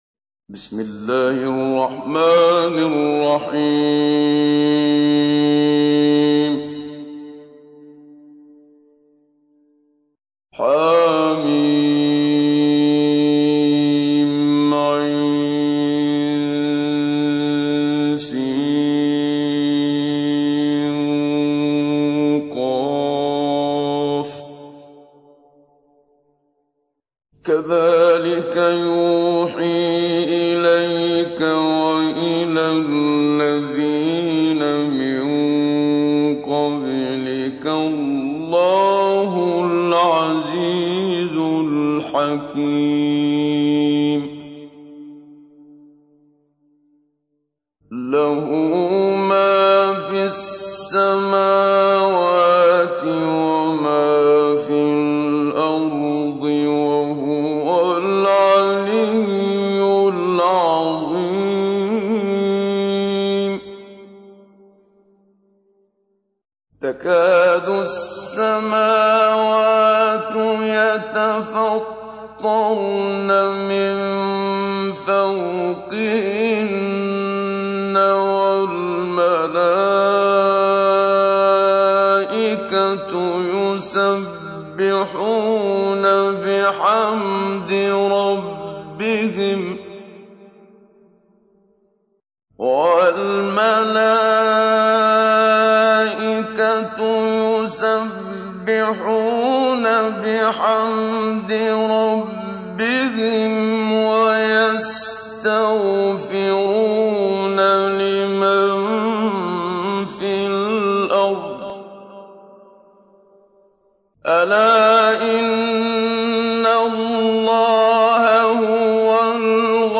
تحميل : 42. سورة الشورى / القارئ محمد صديق المنشاوي / القرآن الكريم / موقع يا حسين